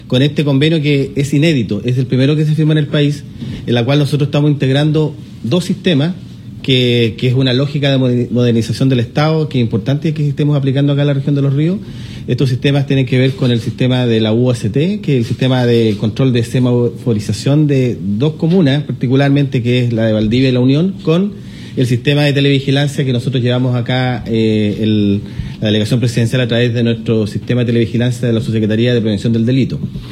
Ante ello, el delegado presidencial Jorge Alvial, precisó que este nuevo sistema de Central de Monitoreo de Cámaras y Drones busca otorgar mejor control y seguridad en la prevención del delito para a los habitantes de la región.